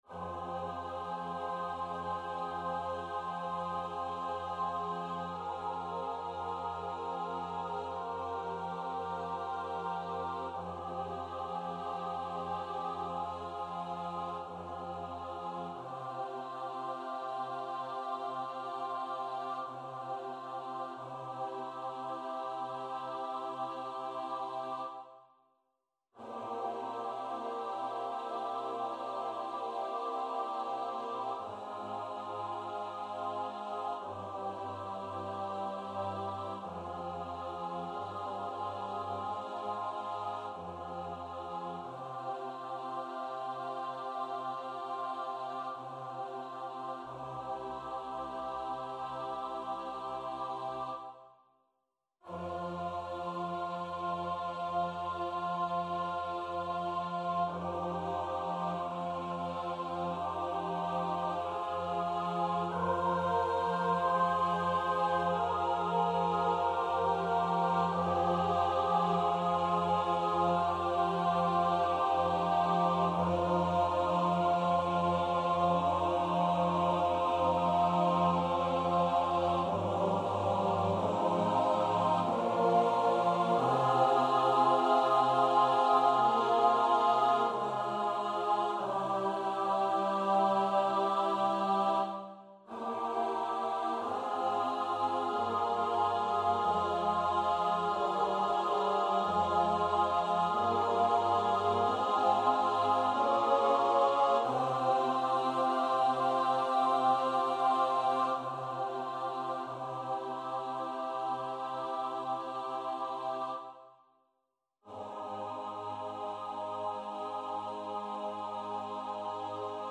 liturgical